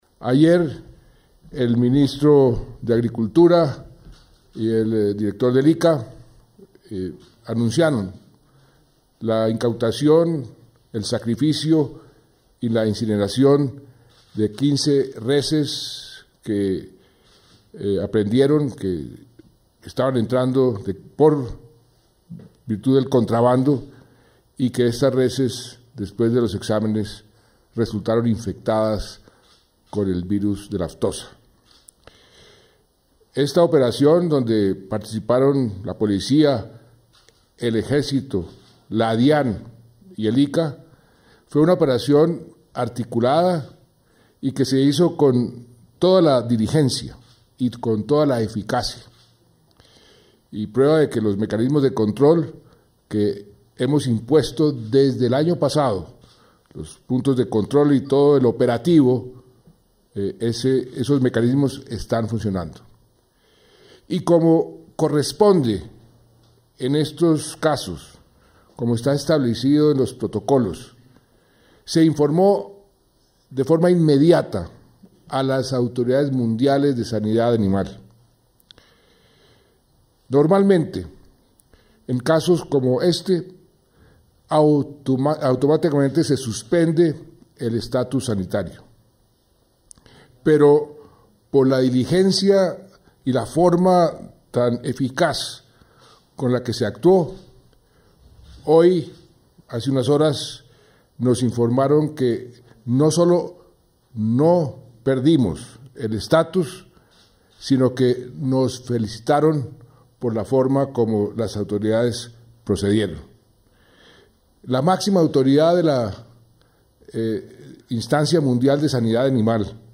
• Descargue aquí la alocución del Presidente de la República: